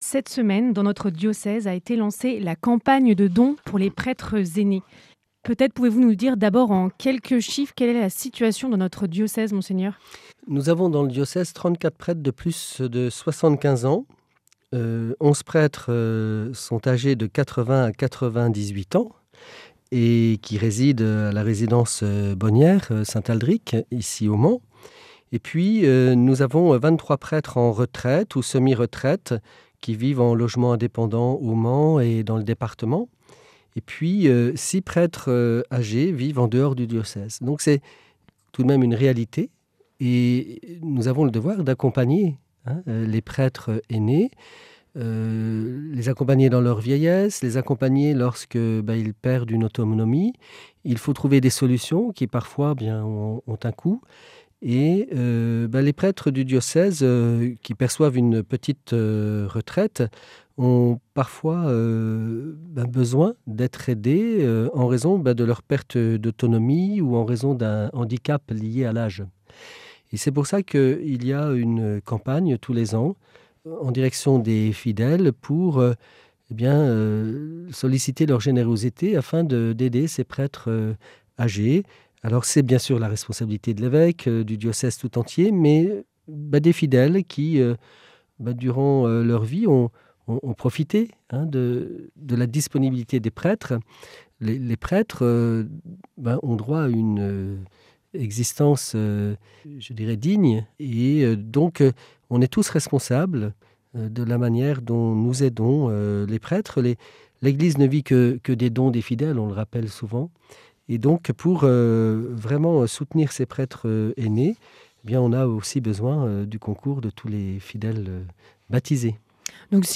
Mgr Vuillemin, au micro de RCF, s'exprime sur la campagne des prêtres ainés 2024